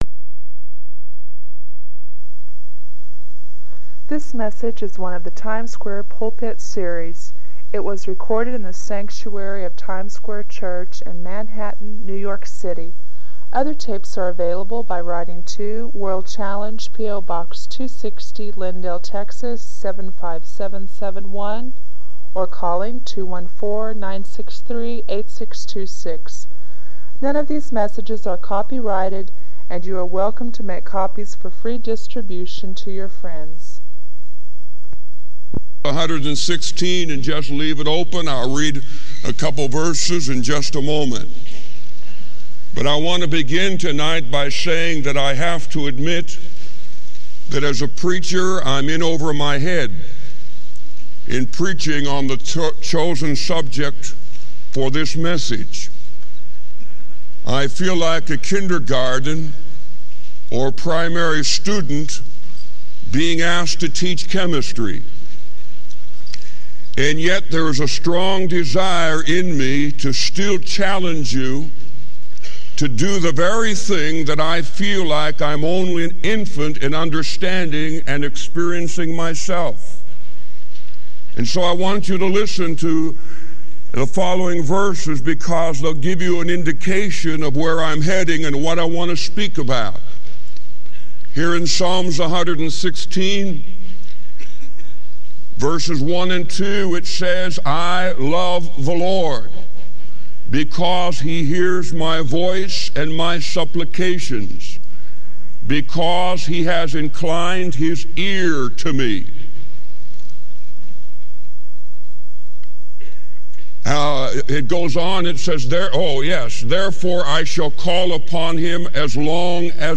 Full Transcript This message is one of the Times Square Pulpit series. It was recorded in the sanctuary of Times Square Church in Manhattan, New York City.